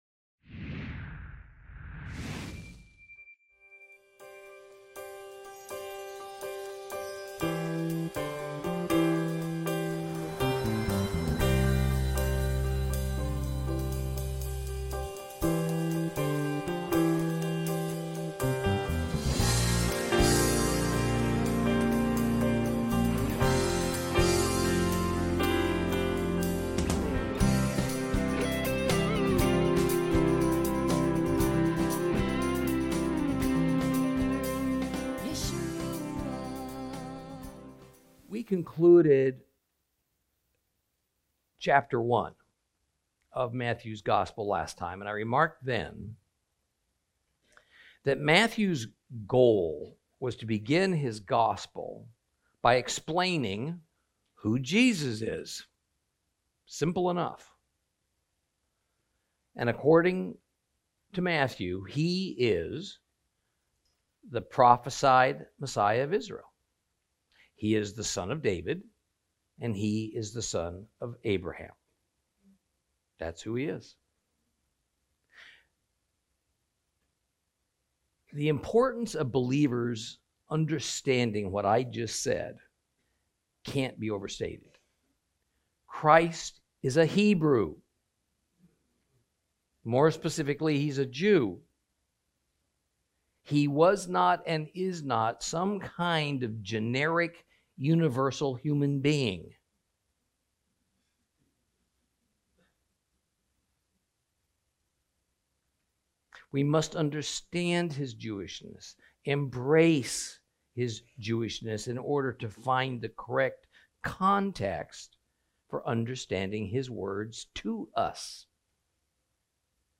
Lesson 4 Ch2